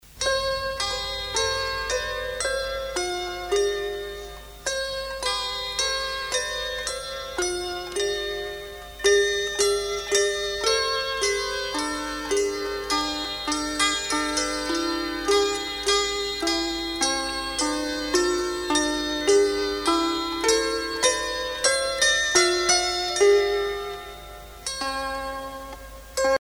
Noël, Nativité
Pièce musicale éditée